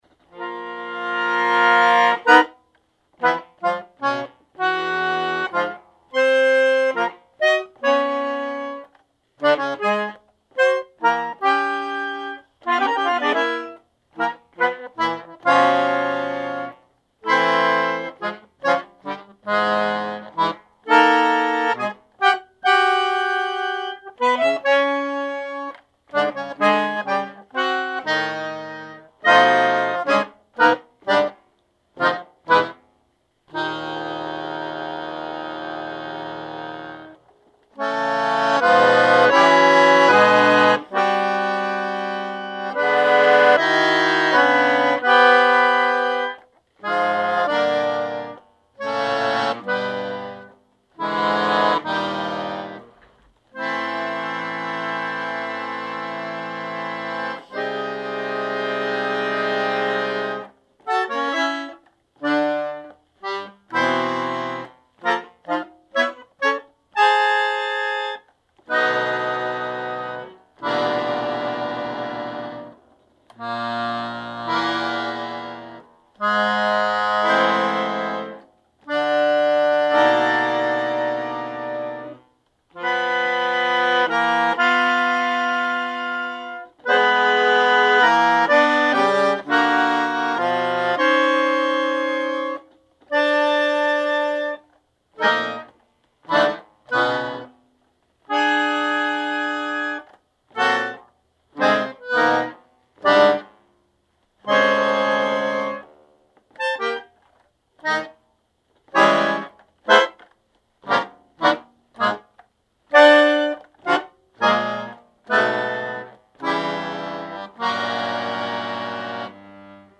Solo Recordings Project
Accordion by the great sound master